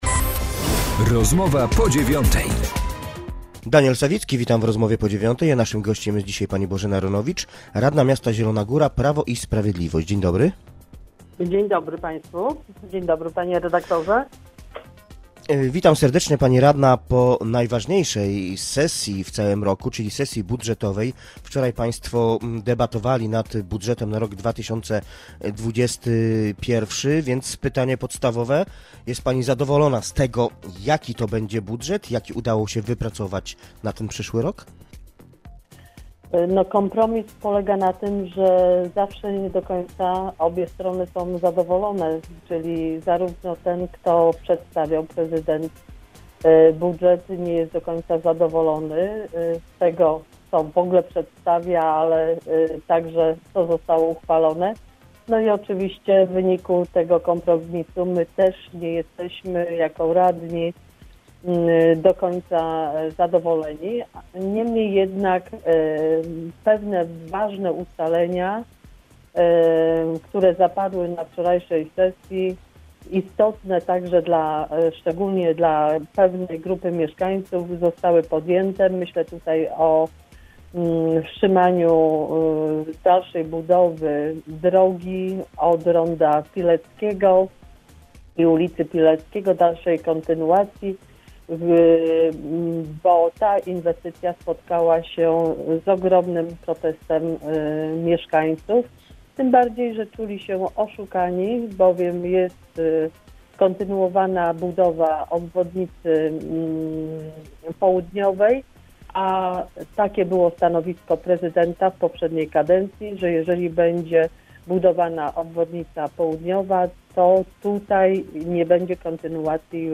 Z radną Prawa i Sprawiedliwości rozmawia